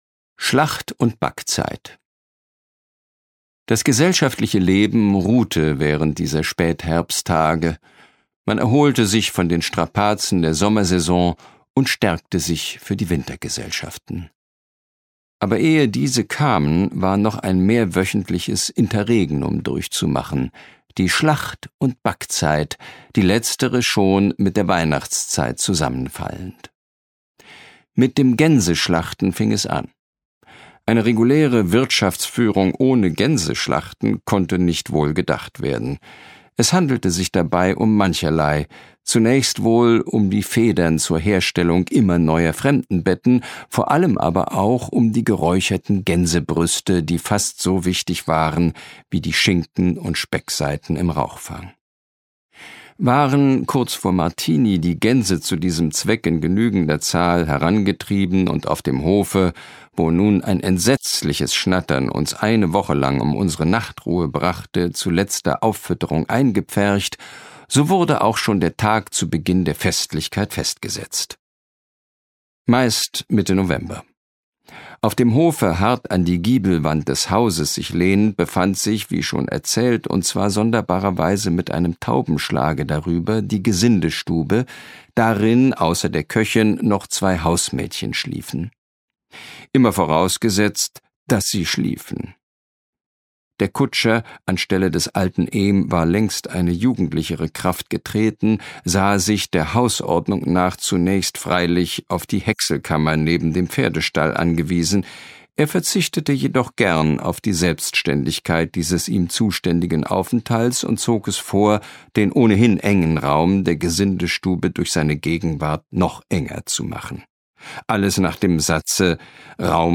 Christian Berkel (Sprecher)
Lesung mit Christian Berkel